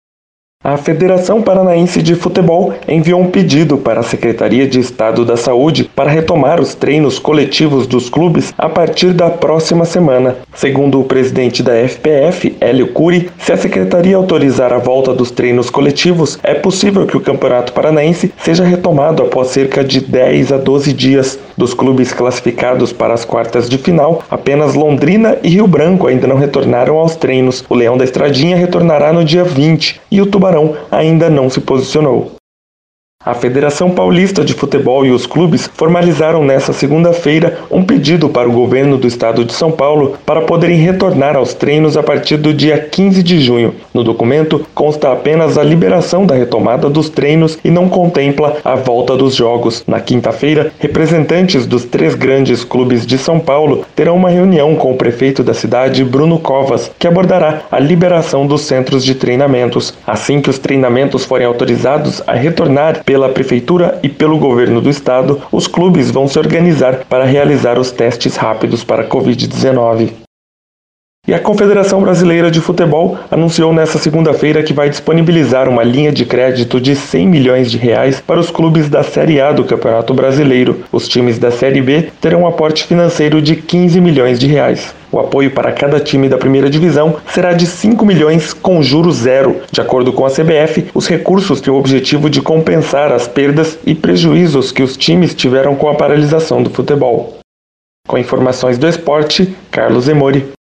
Giro Esportivo SEM TRILHA